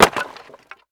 wood_plank_break8.wav